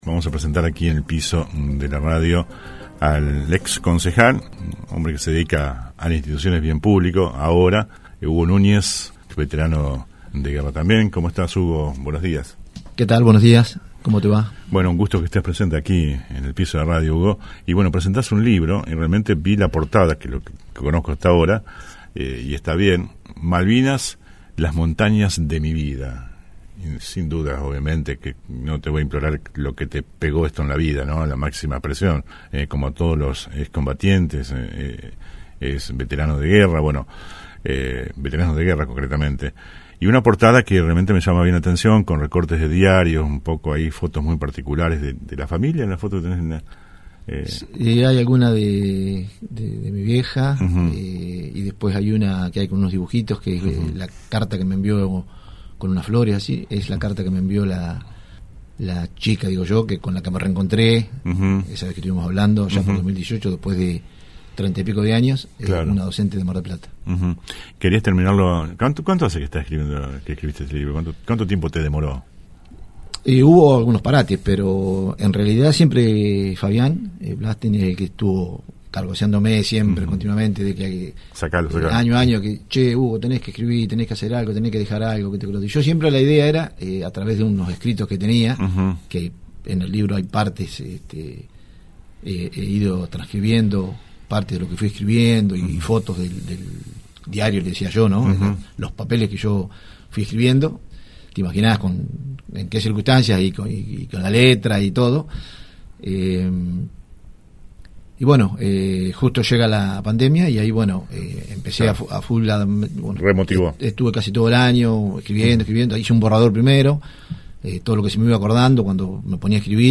AUDIO COMPLETO DE LA ENTREVISTA